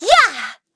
Xerah-Vox_Attack3.wav